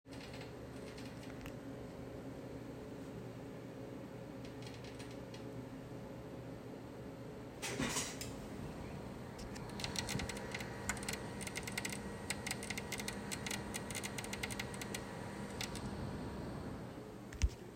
MSI Wasserkühlung macht komische Geräusche
Hallo, seit ein paar Tagen macht meine MSI Coreliquid 240R die in der Audio zu hörenden Geräusche. Hat jemand eine Ahnung woran das liegen könnte und ob ich es reparieren kann?